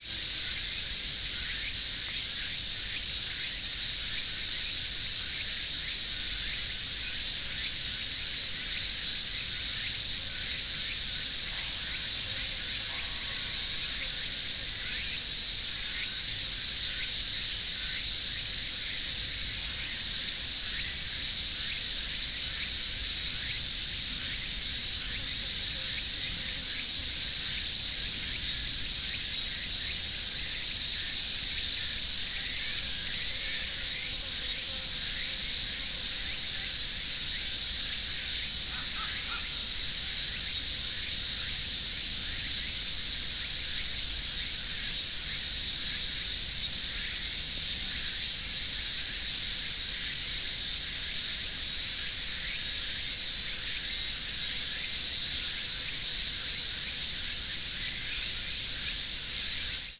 Fukushima Soundscape: Kotori no Mori (Forest for birds)